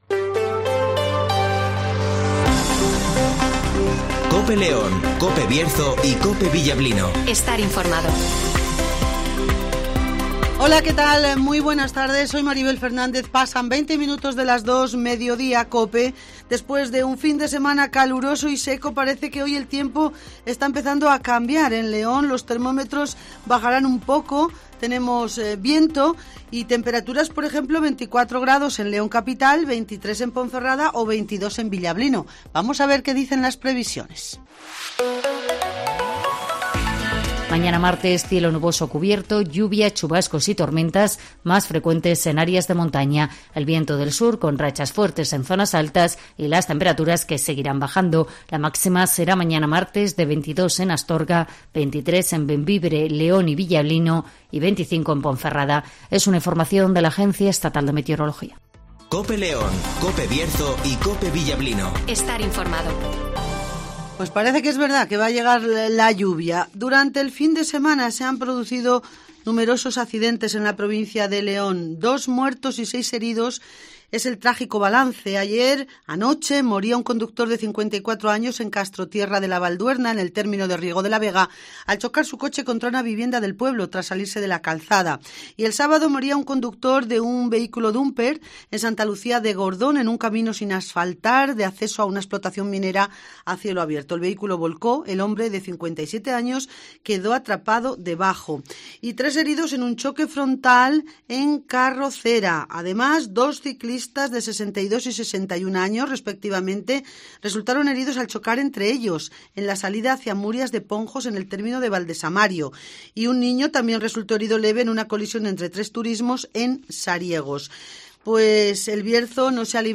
- José Antonio Díez ( Alcalde de León )